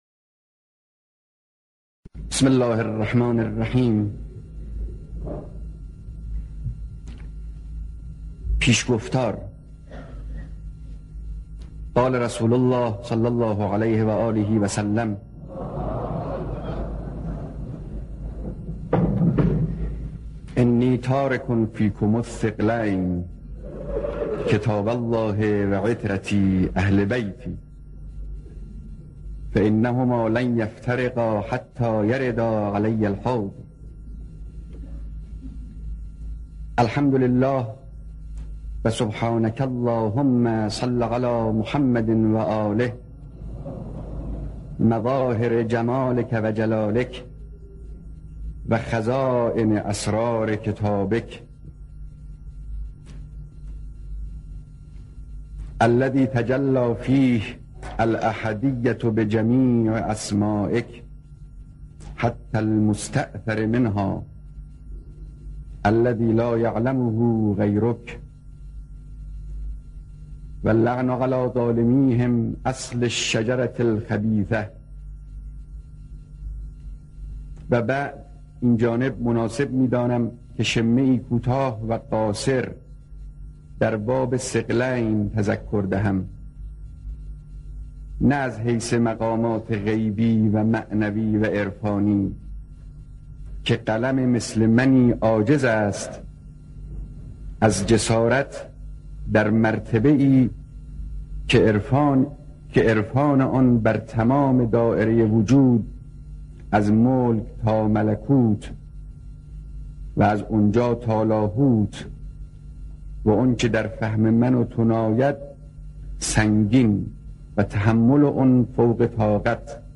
صوت کامل قرائت وصیت‌نامه امام خمینی(ره)